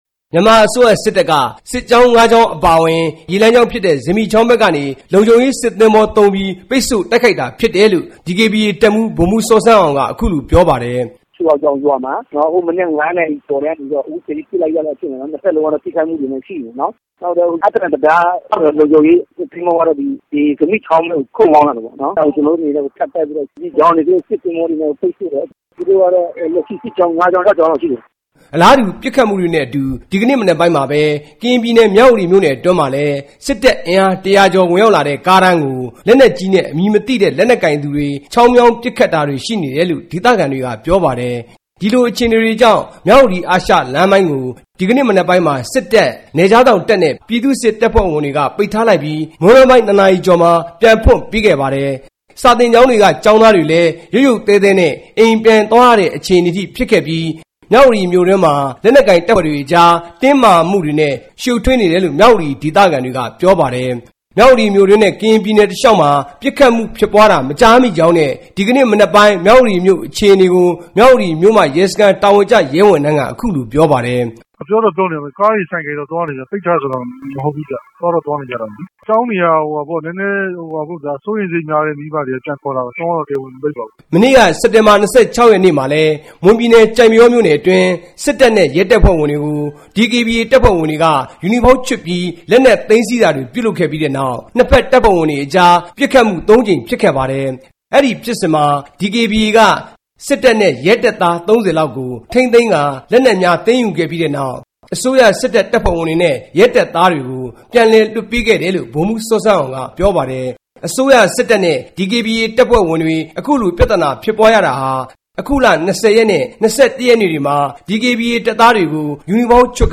မနက် ၁၁ နာရီလောက်မှာဖြစ်ခဲ့တဲ့ အဲဒီခြုံခိုတိုက်ခိုက် သူတွေဟာ ဘယ်အဖွဲ့ကလဲဆိုတာ မသိရသေးတဲ့ အကြောင်း မြို့ခံတစ်ဦးက RFA ကို ပြောပါတယ်။